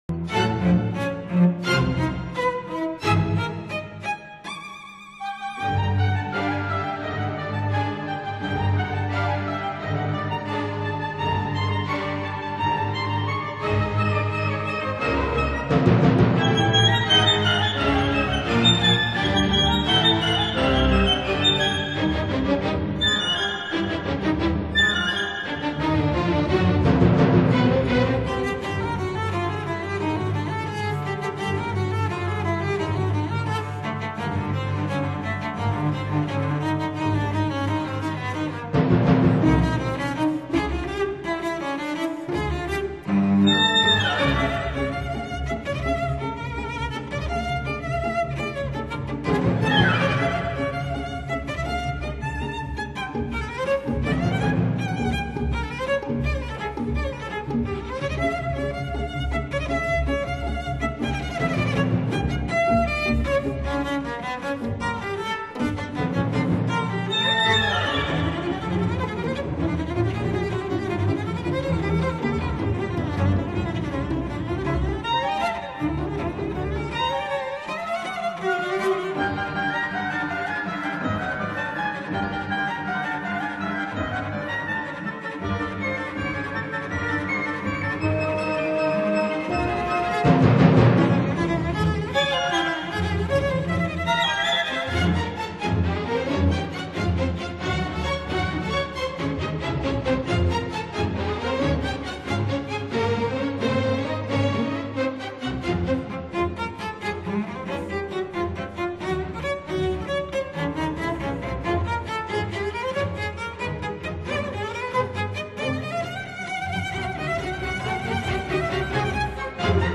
分辑：CD19  大提琴协奏曲